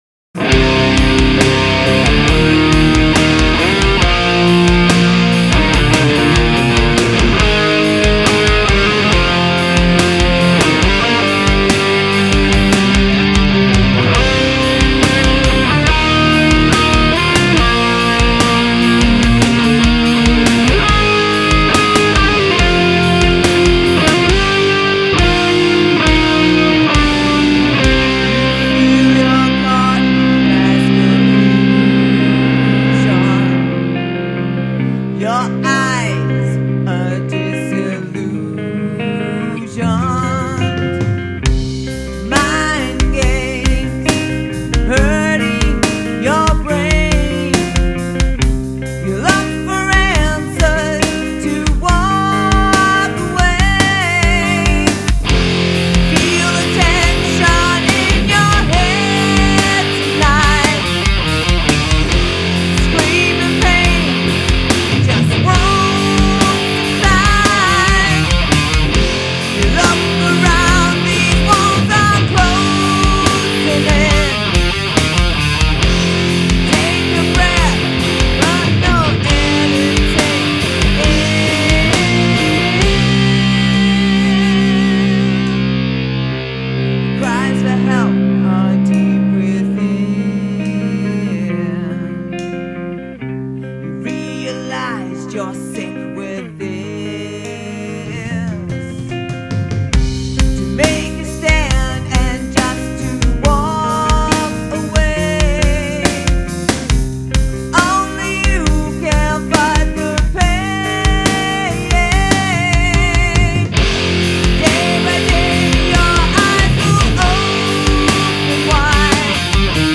Heavy Rhythm 2 sample Heavy Modern Rhythm Channel 3